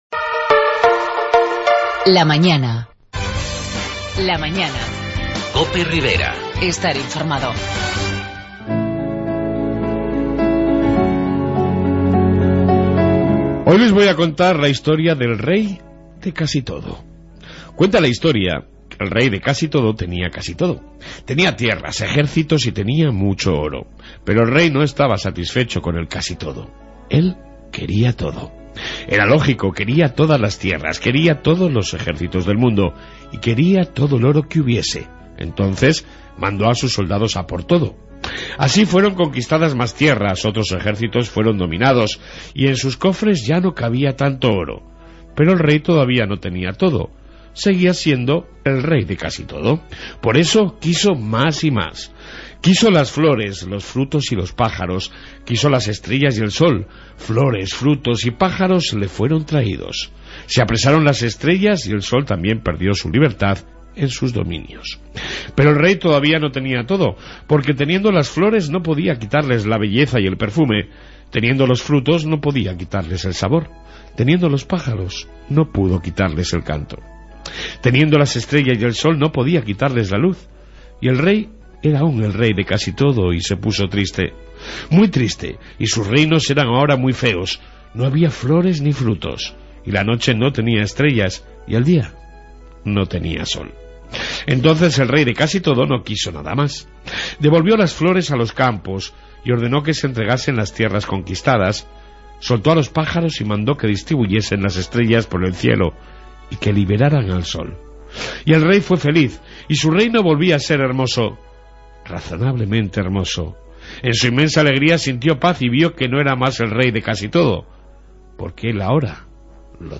Reflexión diaria, entrevista